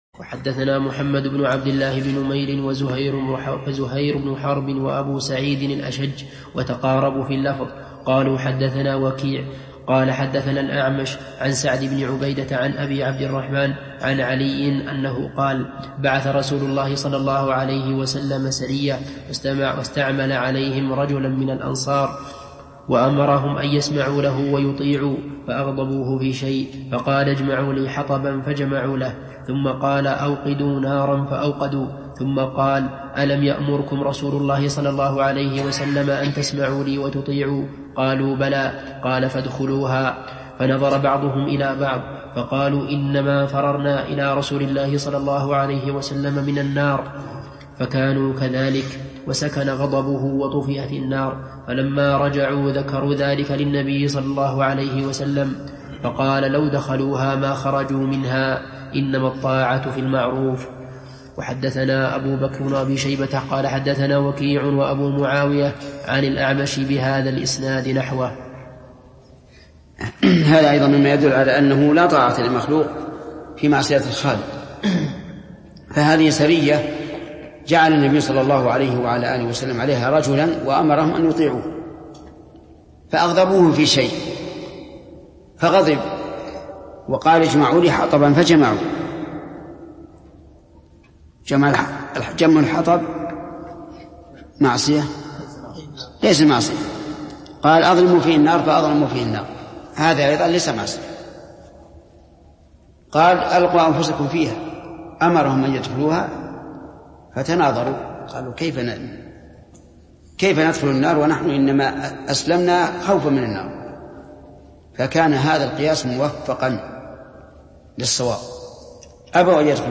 Download audio file Downloaded: 695 Played: 1166 Artist: الشيخ ابن عثيمين Title: التعليق على حديث "إنما الطاعة في المعروف" Album: موقع النهج الواضح Length: 2:27 minutes (652.14 KB) Format: MP3 Mono 22kHz 32Kbps (VBR)